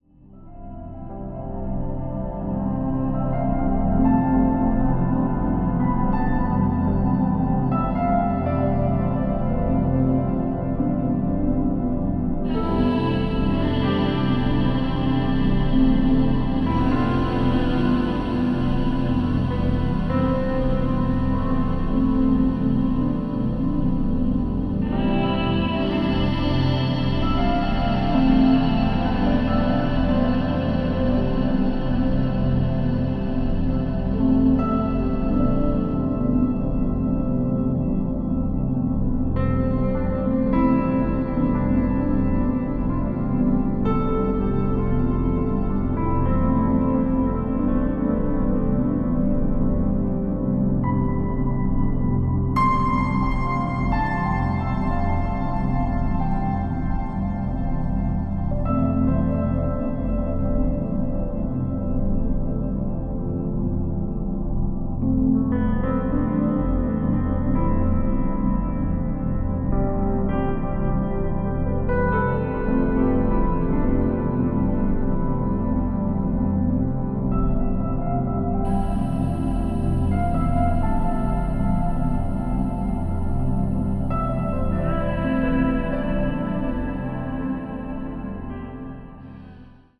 豊かに反復するドローン、細やかなピアノの残響、丹念に作り上げられた音像と１曲１曲がドラマチックに満ちてゆく。